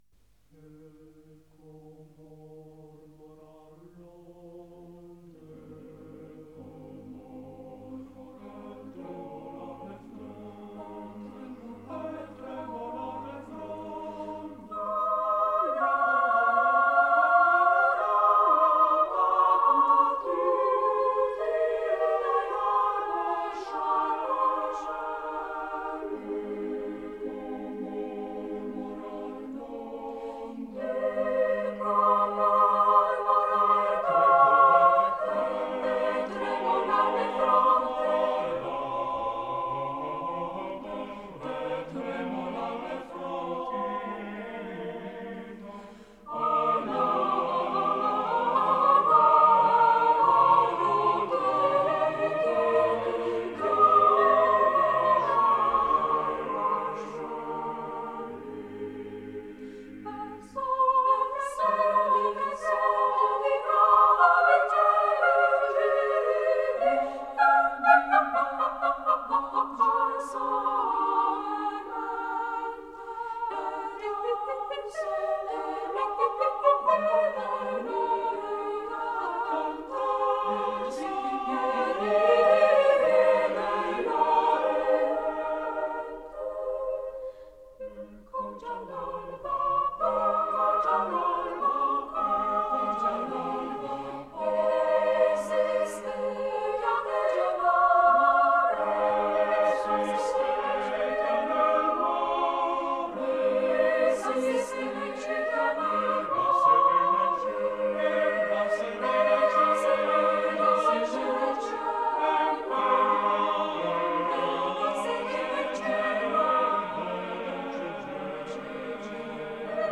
Madrigalul „Ecco mormorar l’onde” de Claudio Monteverdi, interpretat de Corul de Camera MADRIGAL dirijat de Maestrul MARIN CONSTANTIN.